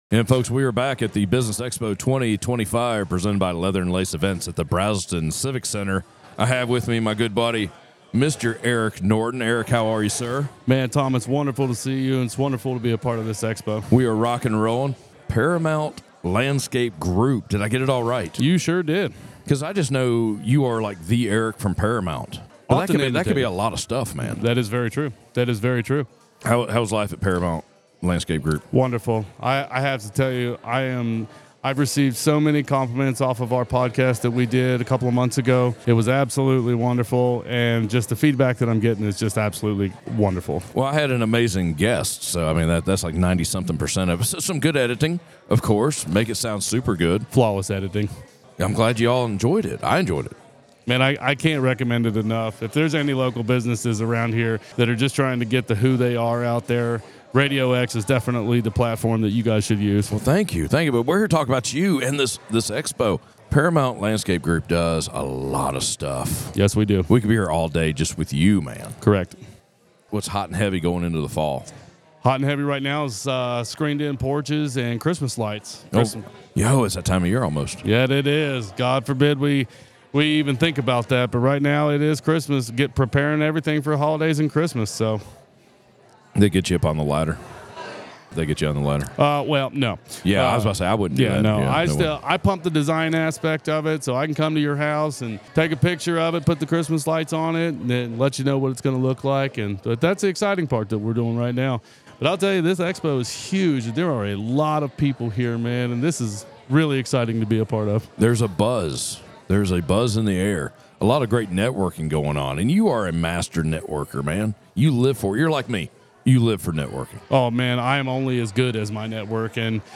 Business Expo 2025 presented by Leather & Lace Events at the Braselton Civic Center
Northeast Georgia Business RadioX – the official Podcast Studio of the Business Expo 2025